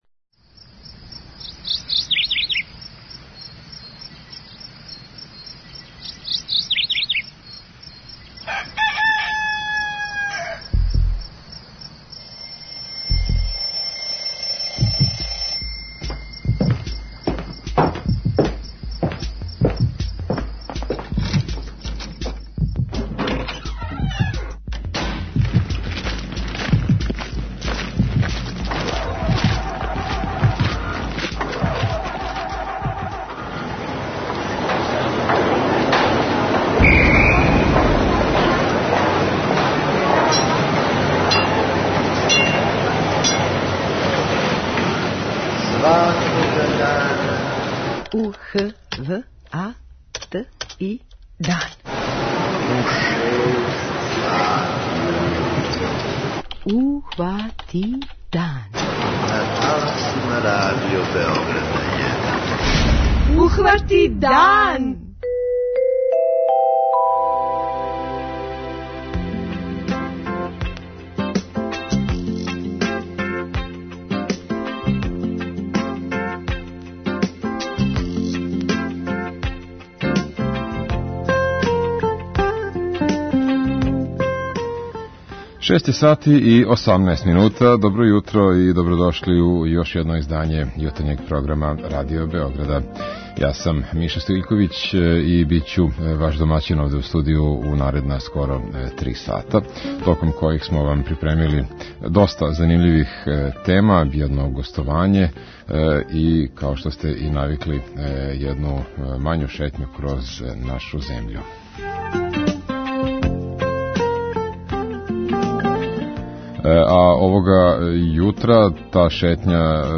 У јутарњем програму говорићемо и о томе зашто је у шабачким селима све више празних кућа које су на продају. Слушаоце ћемо питати по чему ће на личном плану памтити 2018. годину.
преузми : 29.29 MB Ухвати дан Autor: Група аутора Јутарњи програм Радио Београда 1!